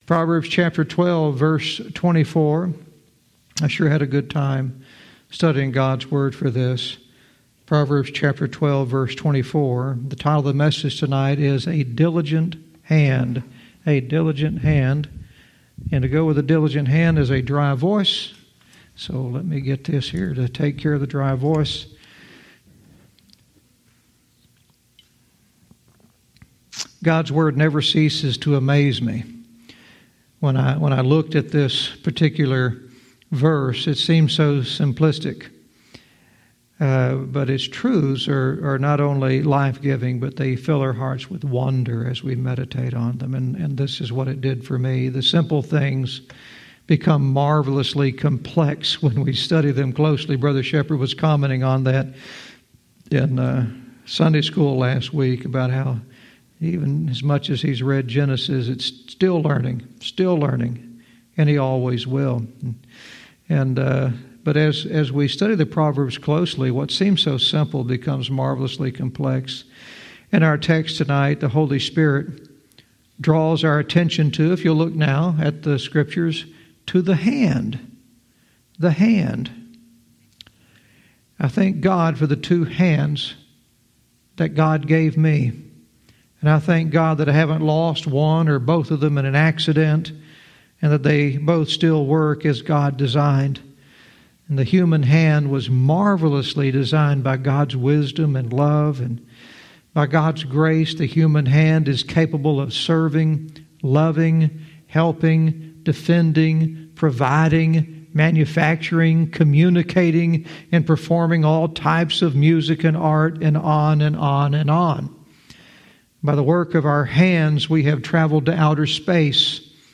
Verse by verse teaching - Proverbs 12:24 "A Diligent Hand"